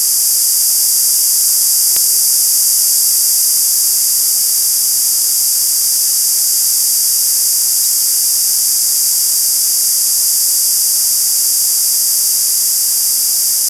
26045 kHz resample DRM-B 36000 Hz
Начало » Записи » Радиоcигналы на опознание и анализ